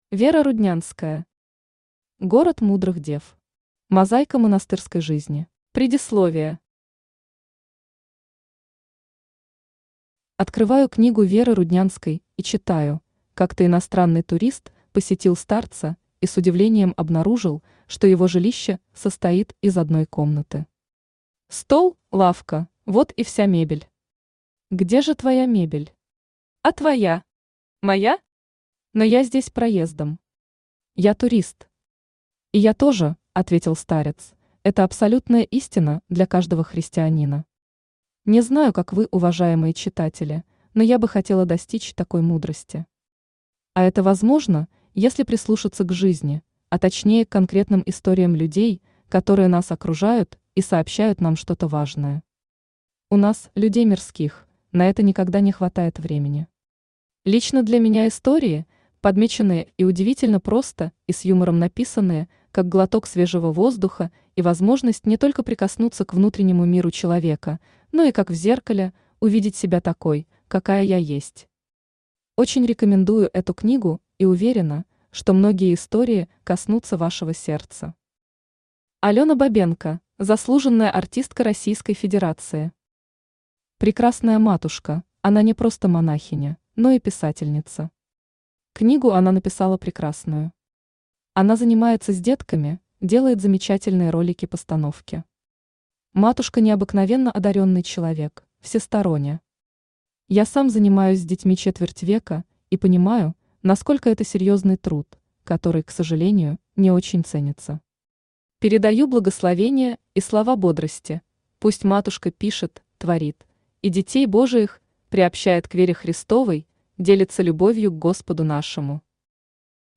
Мозаика монастырской жизни Автор Вера Руднянская Читает аудиокнигу Авточтец ЛитРес.